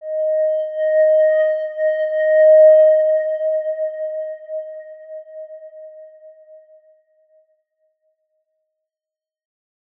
X_Windwistle-D#4-pp.wav